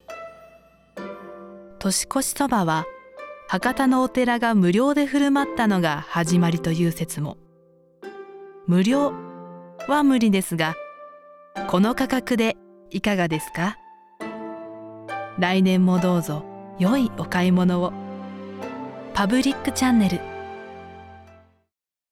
ボイスサンプル